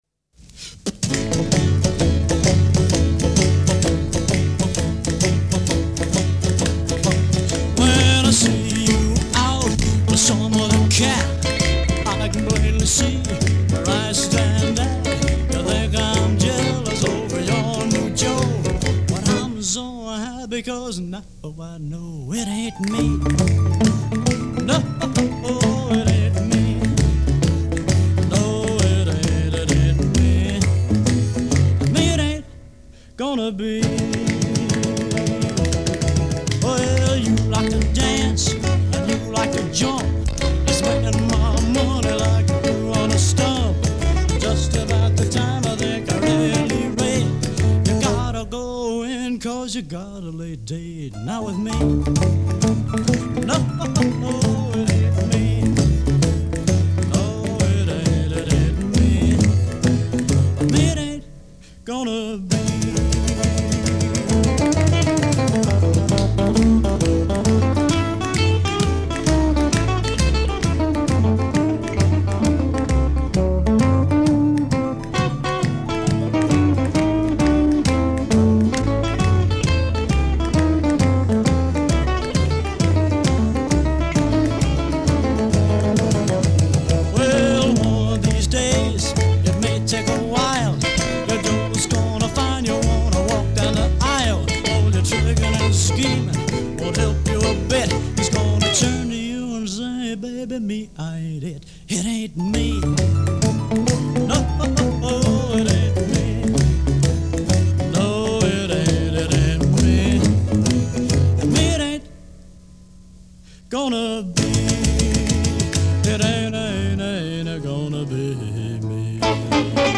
finger-snapping instead of a drummer